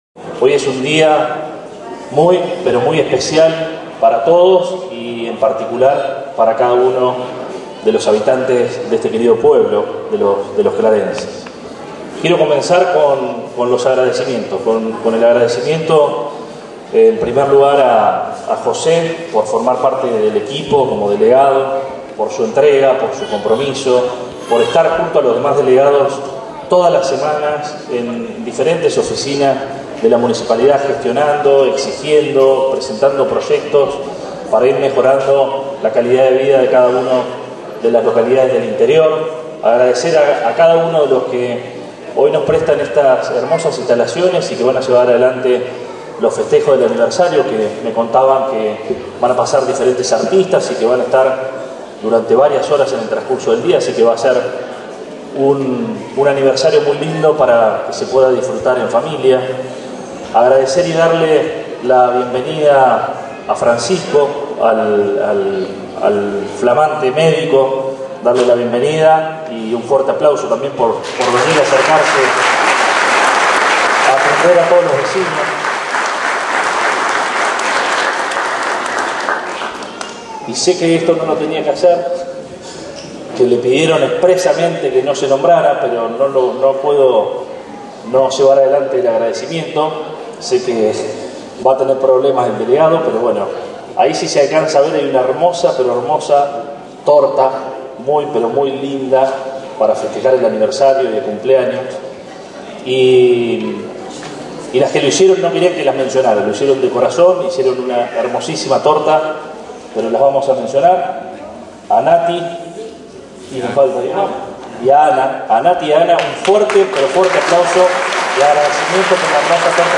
La pujante localidad de Claraz cumplió este martes sus primeros 113 años de vida y, por tal motivo, se desarrollaron una serie de festejos a lo largo de la tarde que contaron con la presencia del propio intendente Arturo Rojas, encargado de dar las palabras alusivas en el marco del nuevo aniversario.
La Asociación Civil de Fomento, a cuyos integrantes se les hizo entrega de la certificación de habilitación definitiva después de tantos años de lucha y compromiso para con la vida institucional del pueblo, se transformó en el epicentro de la celebración por las malas condiciones climáticas reinantes.